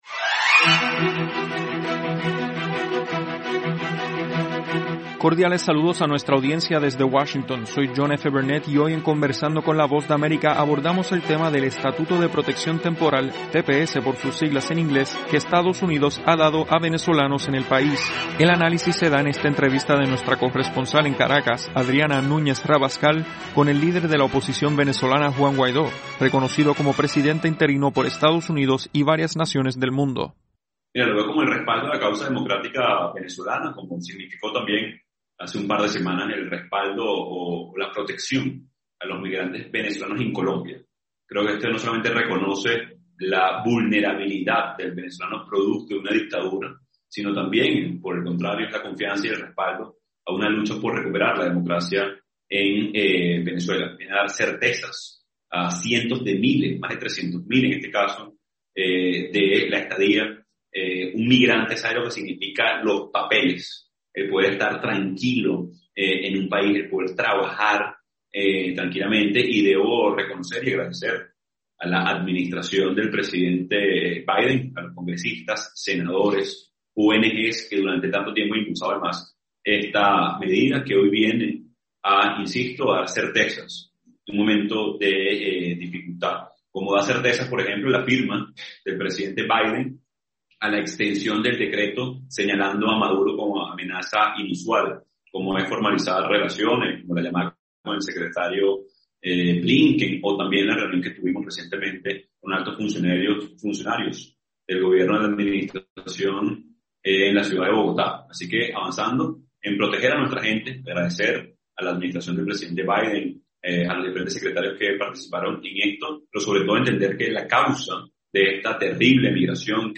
Conversamos con Juan Gauido, líder opositor reconocido como presidente interino de Venezuela por EE.UU. y sus valoraciones sobre el estatuto de protección temporal para venezolanos en Estados Unidos y Colombia.